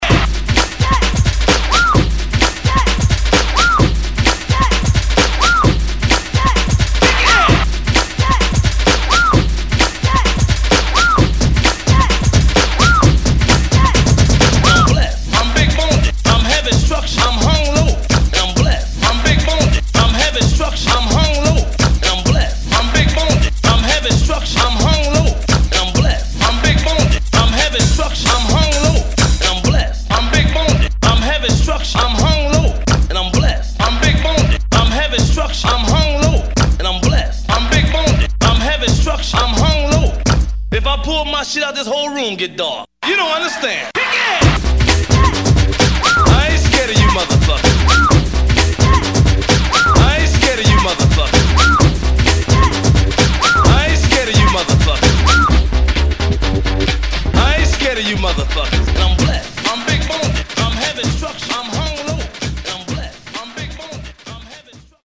Alabama's interpretation of Baltimore Club music